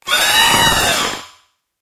Cri de Corvaillus dans Pokémon HOME.